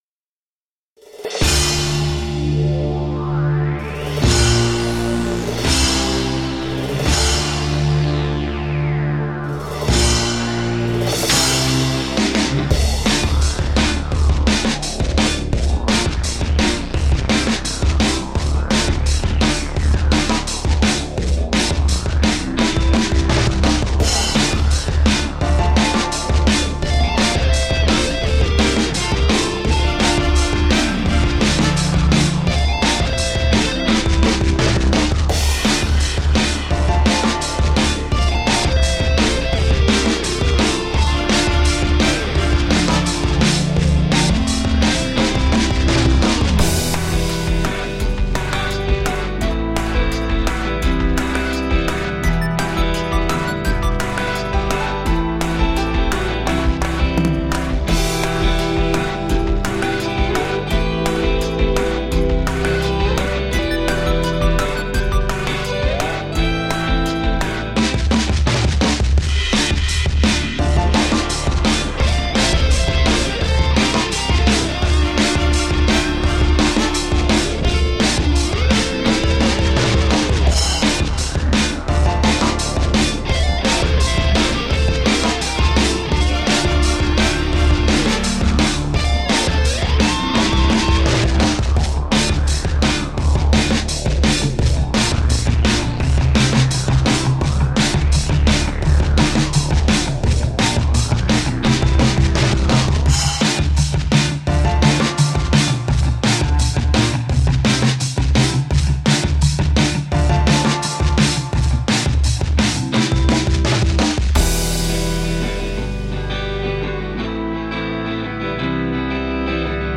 Experimental electro blues at its finest.
Enjoy an instrumental electronica/blues/jazz/rock hybrid!
Tagged as: Electronica, Jazz, Alt Rock, Instrumental